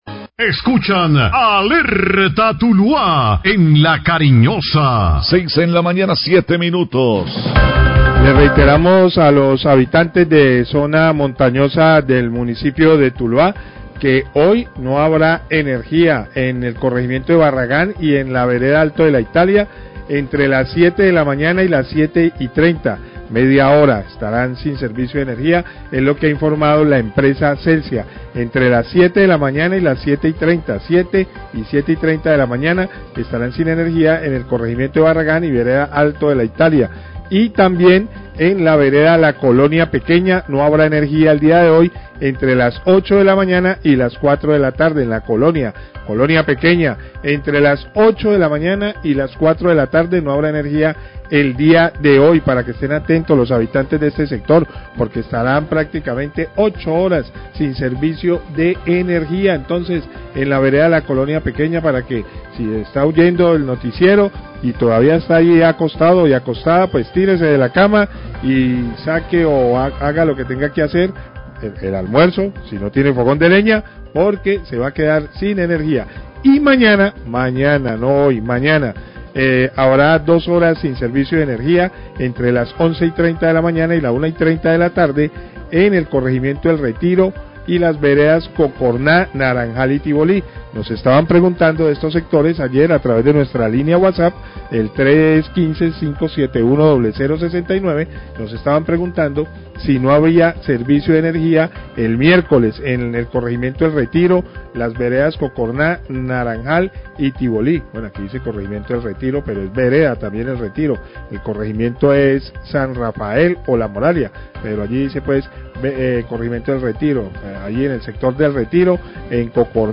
Radio
Periodista reitera los corregimientos y veredas del municipio de Tuluá que tendrán hoy cortes programados de energía por trabajos de mantenimiento.  Aclara que las veredas Naranjal y Tivoli, que habían preguntado ayer por los cortes, hoy si tendrán suspensión de energía.